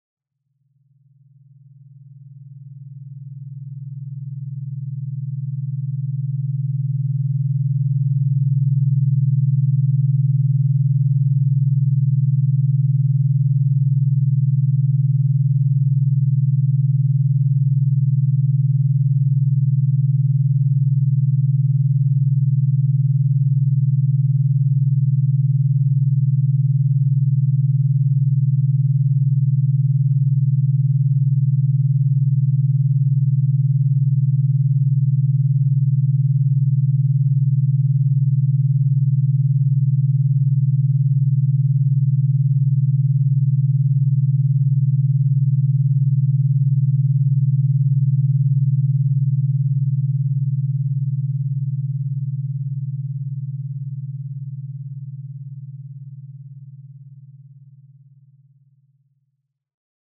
Pure beta waves to improve memory and cognitive performance.
Energizer+(pure+beta+waves).mp3